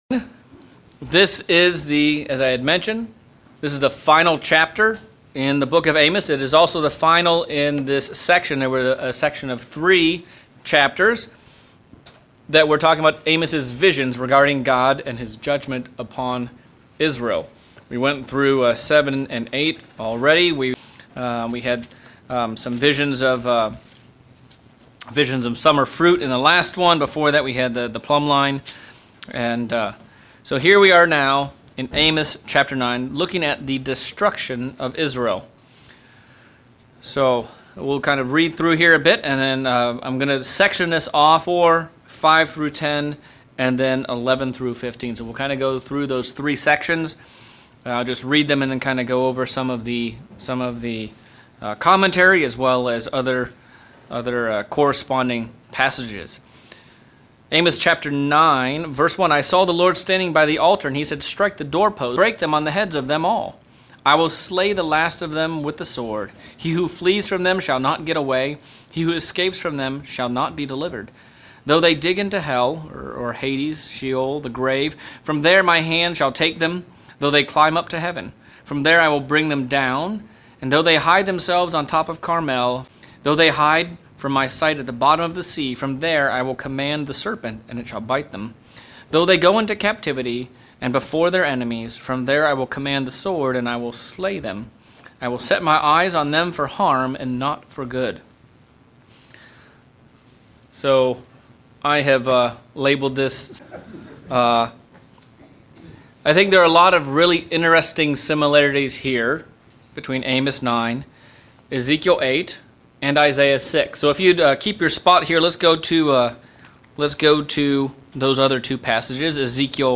June 2024 Bible Study - Amos Chapter 9